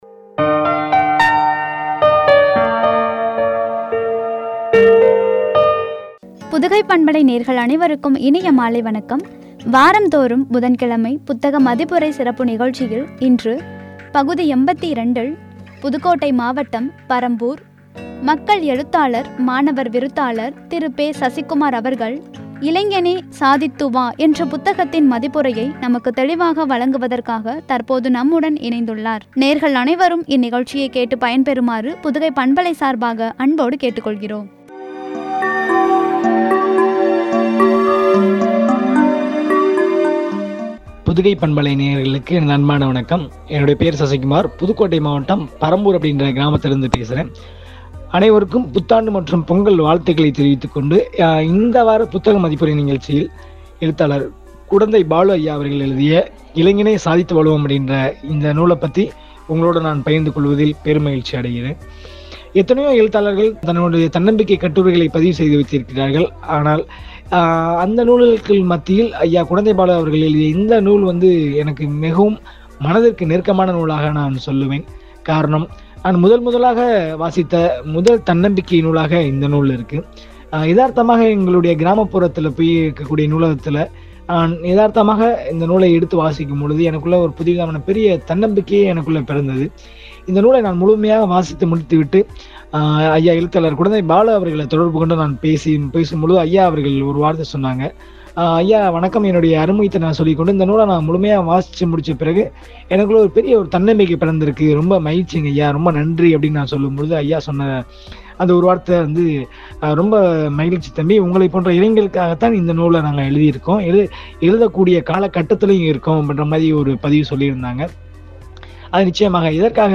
குறித்து வழங்கிய உரை.